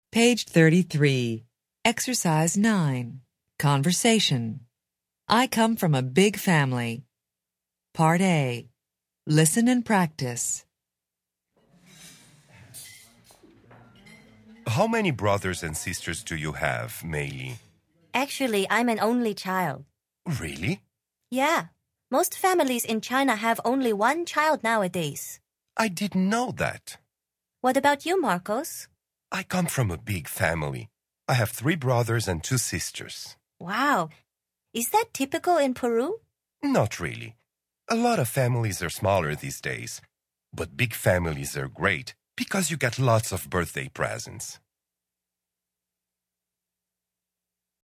Interchange Third Edition Level 1 Unit 5 Ex 9 Conversation Track 15 Students Book Student Arcade Self Study Audio